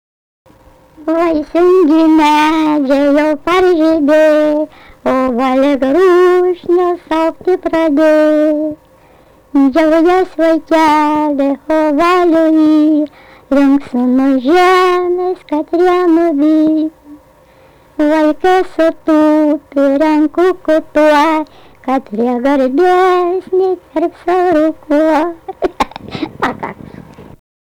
Dalykas, tema daina
Erdvinė aprėptis Ryžiškė Vilnius
Atlikimo pubūdis vokalinis